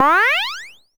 retro_jump_collect_bonus_03.wav